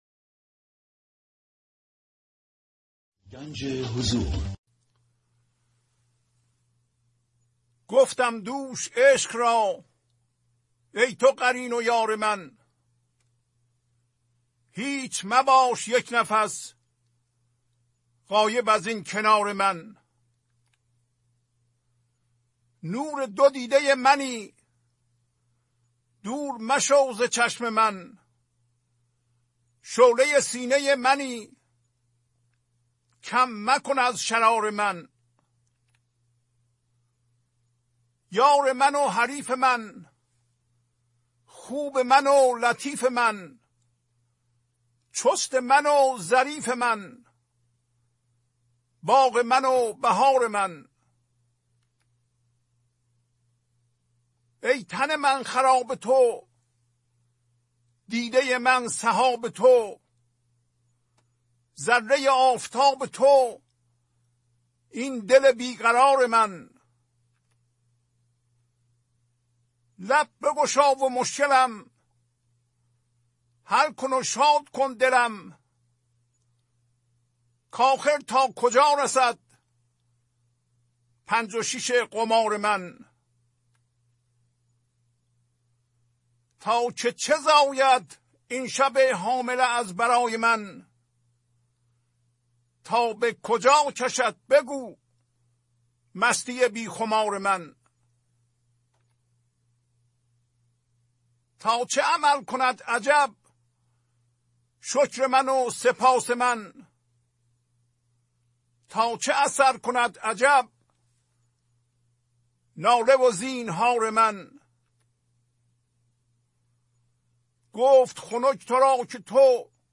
خوانش تمام ابیات این برنامه - فایل صوتی
929-Poems-Voice.mp3